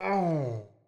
SFX_Mavka_Hit_Voice_07.wav